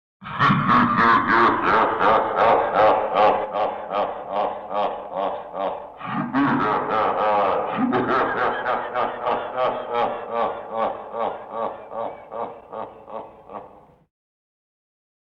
smeh1.mp3